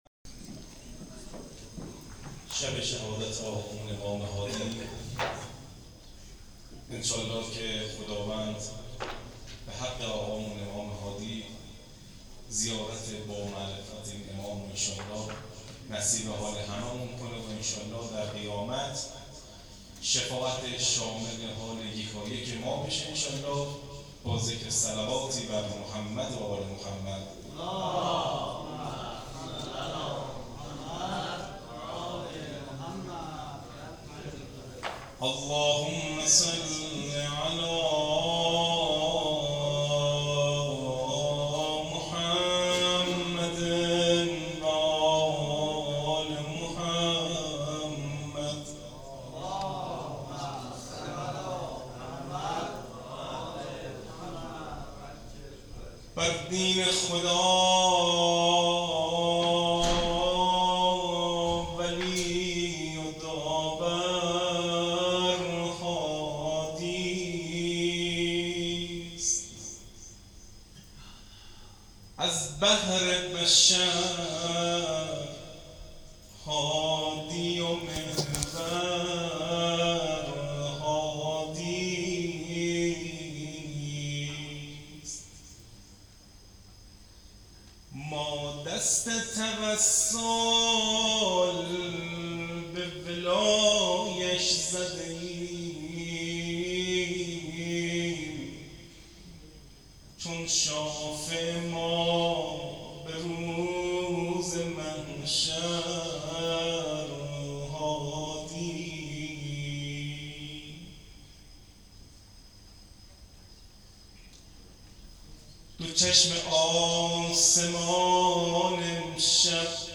مراسم شب شهادت امام هادی علیه السلام